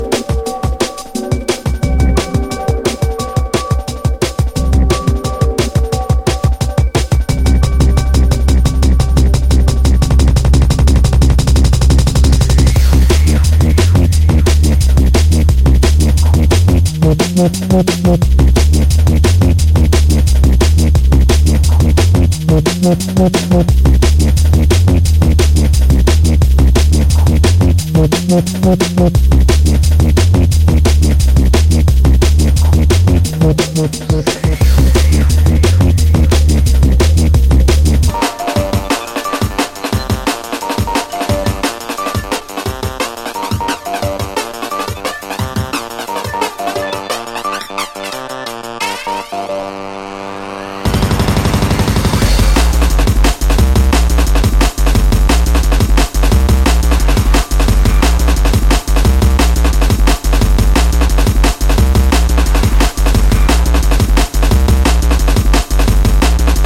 Mixed CD